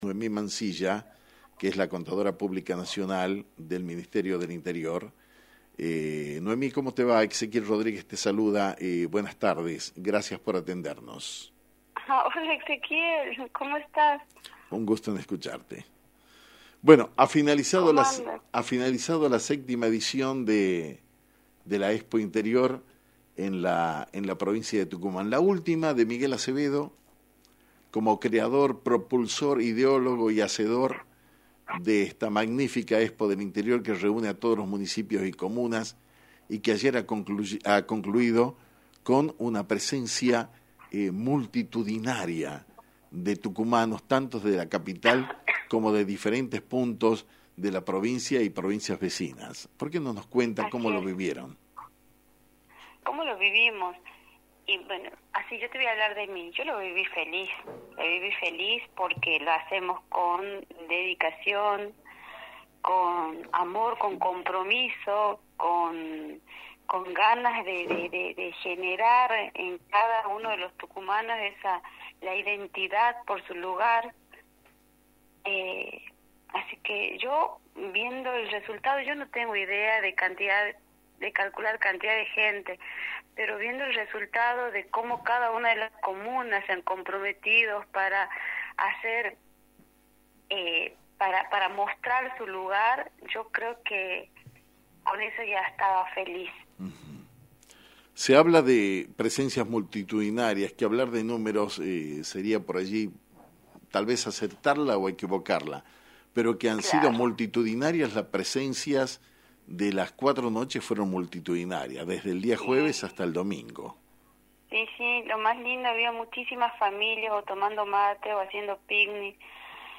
En dialogo con Actualidad en Metro por Metro 89.1 mhz